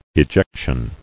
[e·jec·tion]